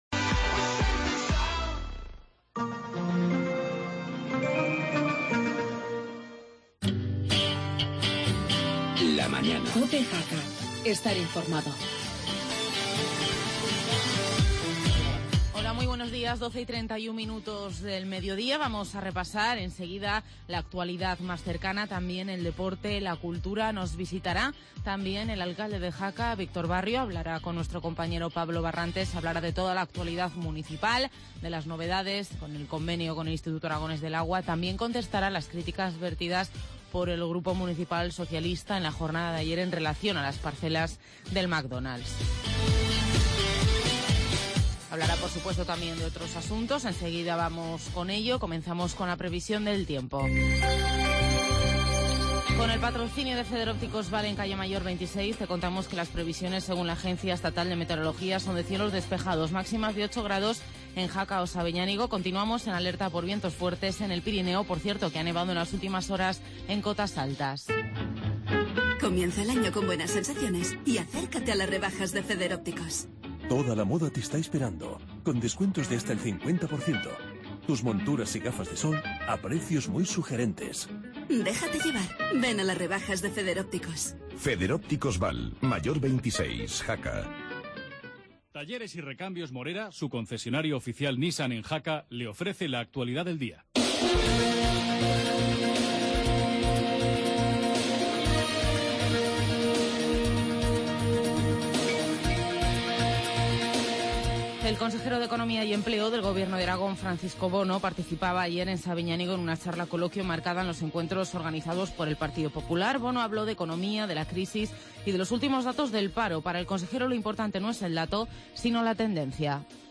AUDIO: Entrevista al alcalde Víctor Barrio.